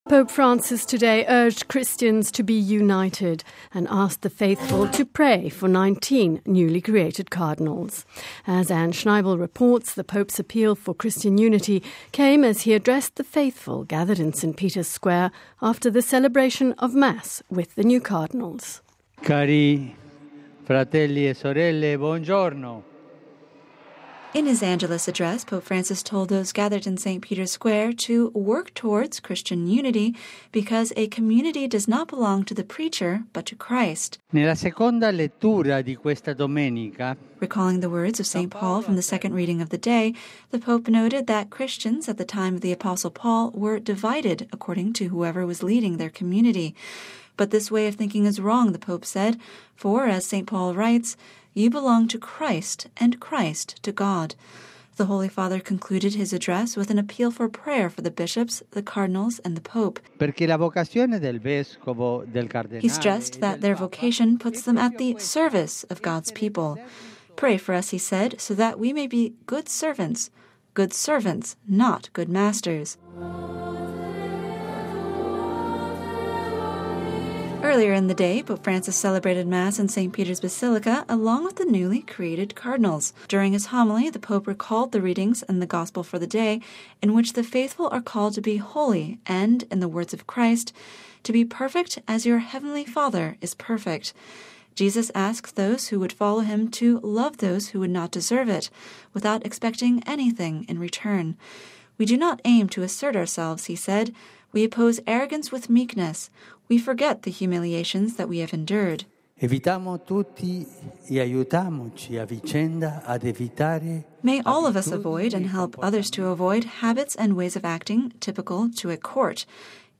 (Vatican Radio) Following the celebration of Mass on Sunday morning with the 19 new Cardinals, Pope Francis greeted the crowds in St. Peter’s Square gathered for the Angelus prayer.